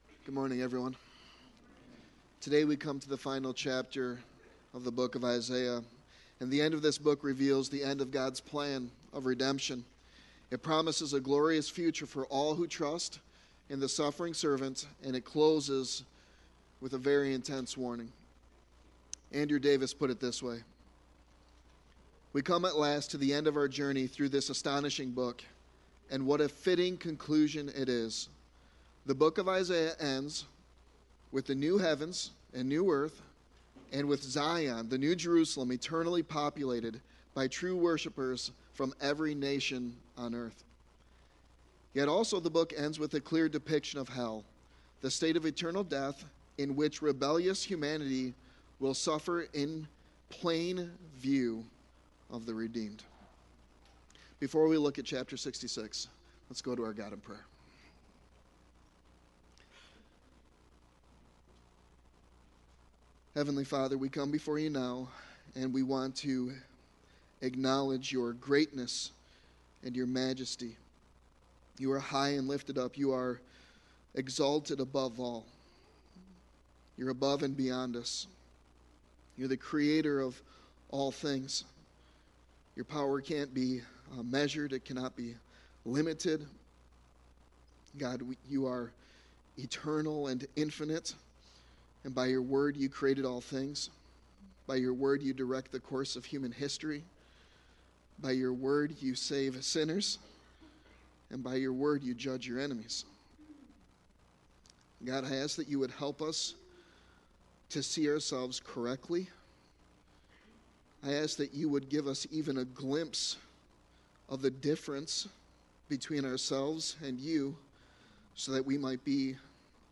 Sermon Text: Isaiah 66:1-24